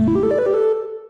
Techmino/media/effect/chiptune/spin_3.ogg at 03eafb4881a6738ec60abc50dfed021b6f80fda1